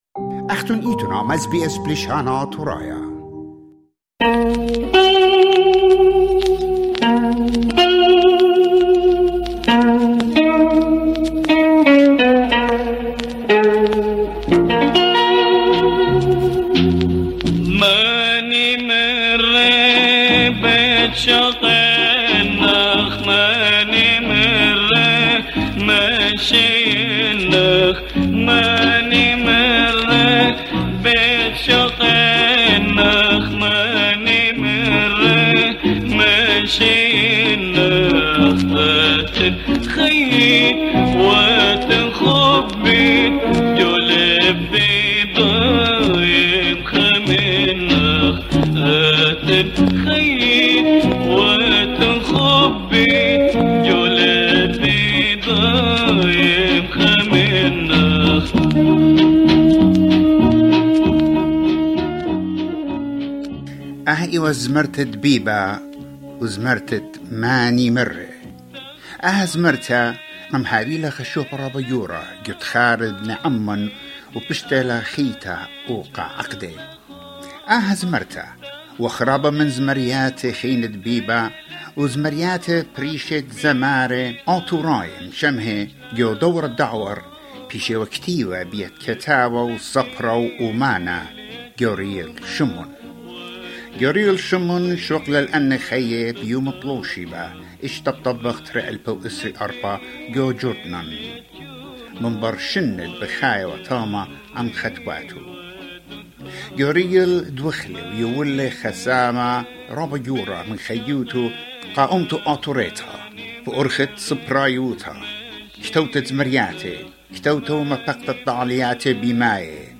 SBS Assyrian honours the memory of Gorial Shimoun, an esteemed Assyrian writer, theatre director, songwriter, and media personality, who passed away on 6 August, 2024, in Jordan. We interviewed